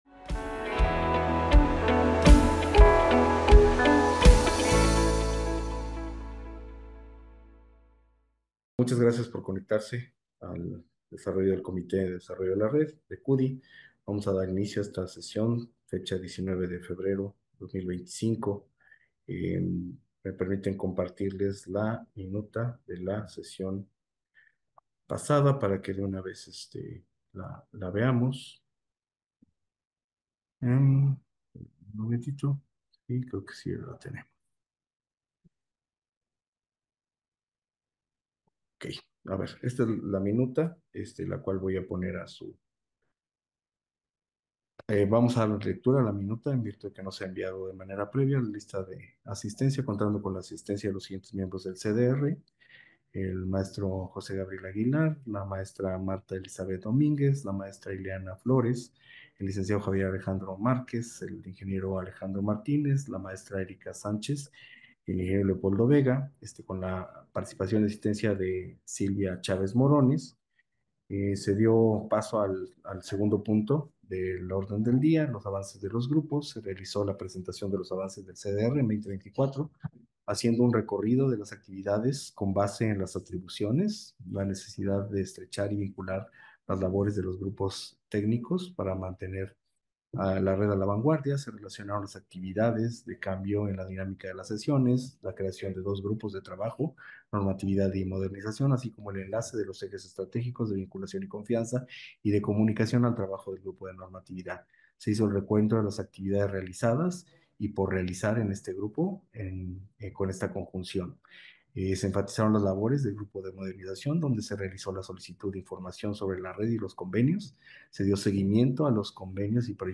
Audio de la reunión